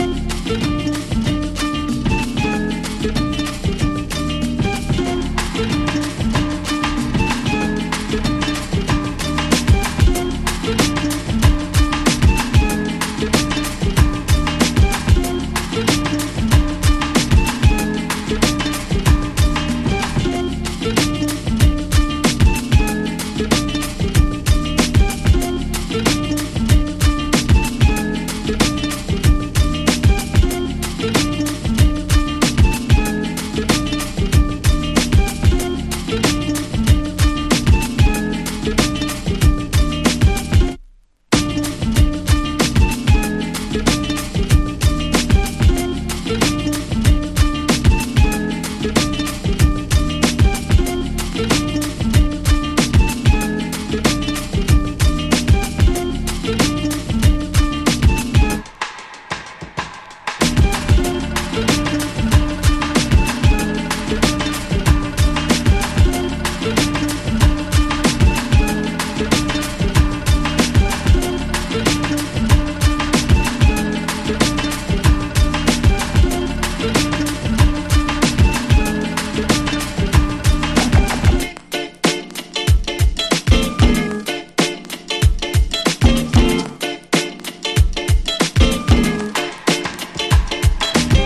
最強サンバ・ヒップホップをリミックス！